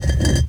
rock_m2.wav